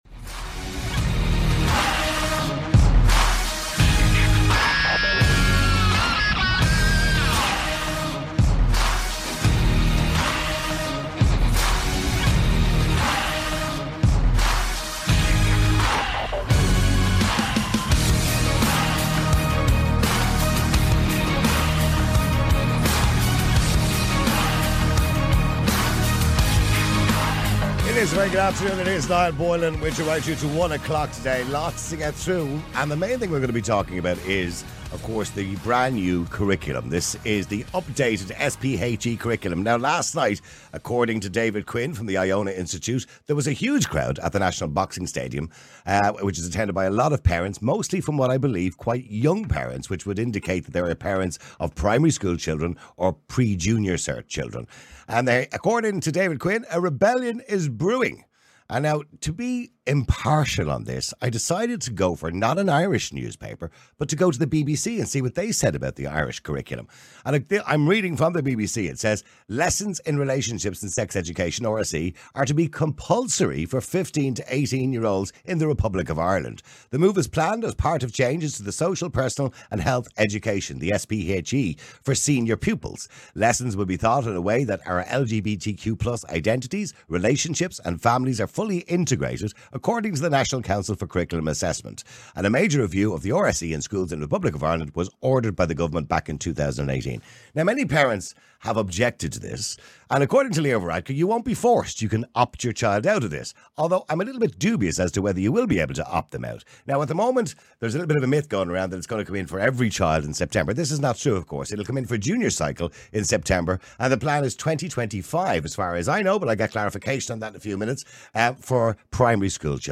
#47 Should Lgbtq+ Issues Be Part Of Sex Education In Schools? (Interviews only audio) – My CMS